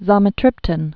(zŏlmĭ-trĭptn)